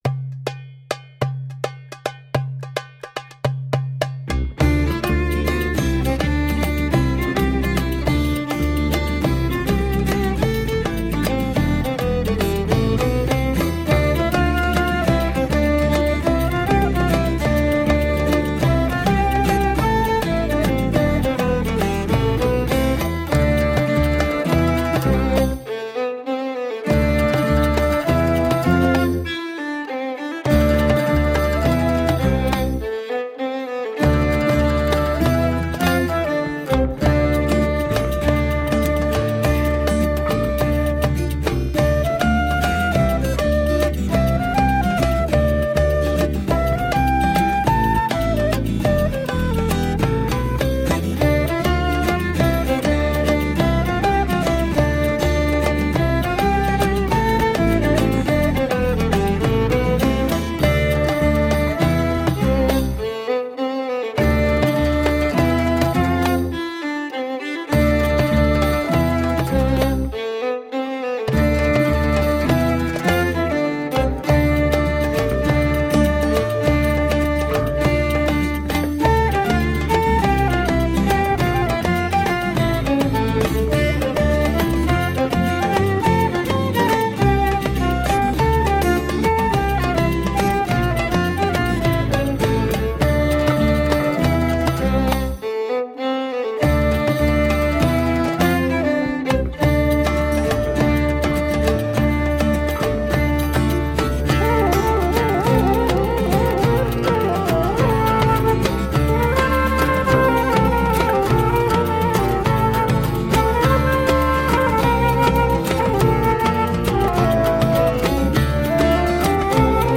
Sultry and percussive mid-east fusion.